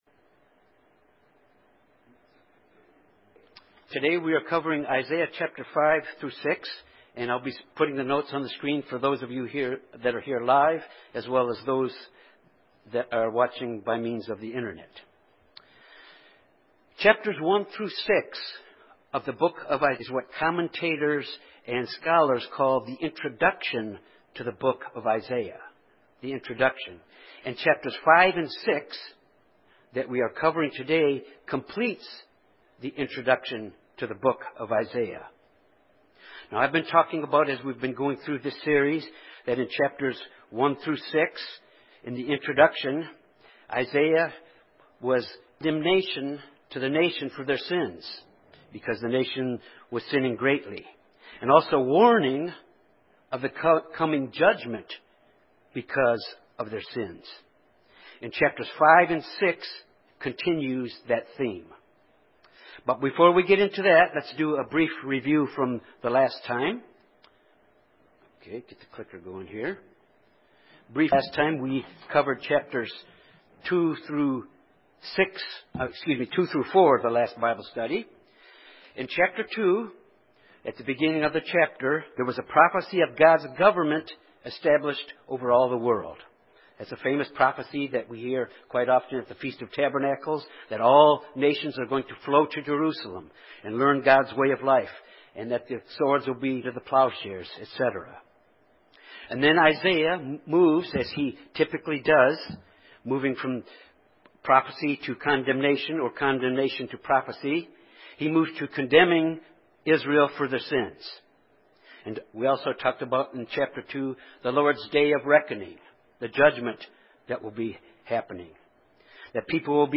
This Bible study examines chapters 5-6 which concludes the introduction of the book of Isaiah.